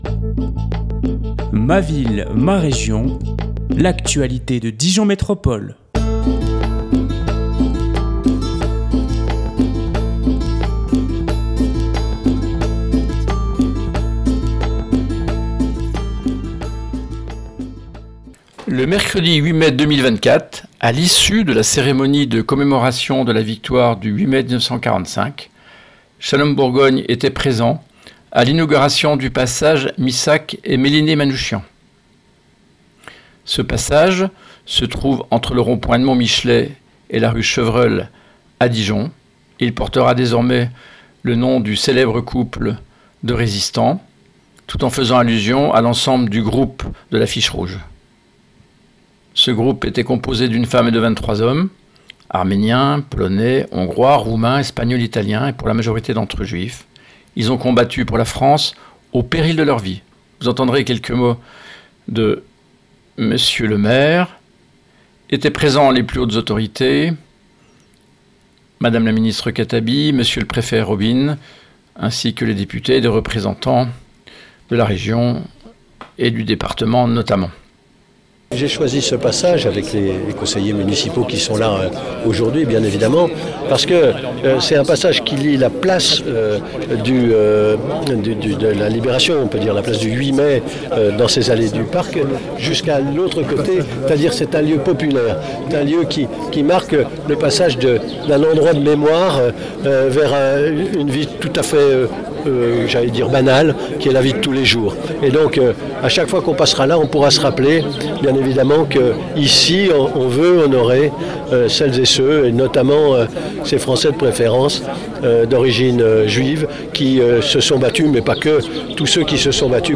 Non loin de la pisicine du Carrousel a été inauguré le passage Missak-et-Mélinée-Manouchian.
François Rebsamen repondait aux questions des journalistes.